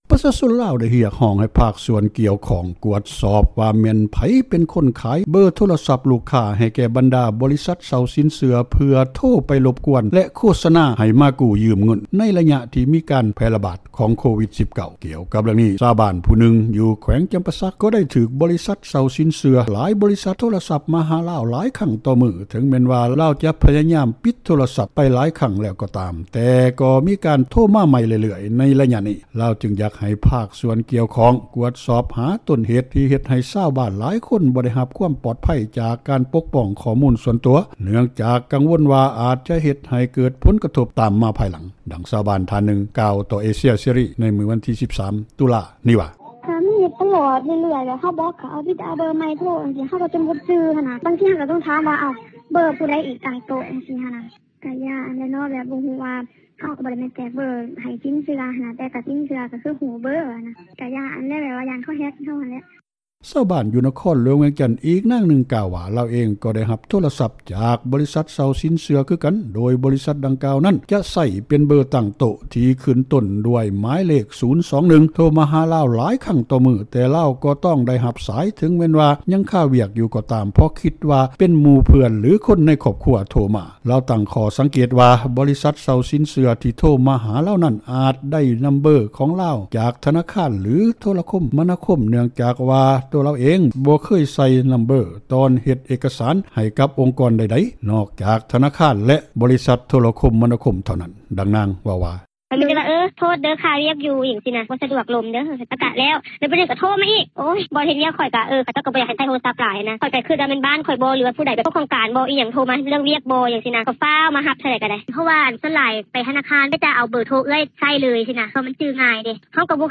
ຕາມຄຳເວົ້າ ຂອງຊາວບ້ານຄົນນີ້ ຕໍ່ວິທຍຸເອເຊັຽເສຣີ ໃນວັນທີ 13 ຕຸລາ ນີ້ວ່າ: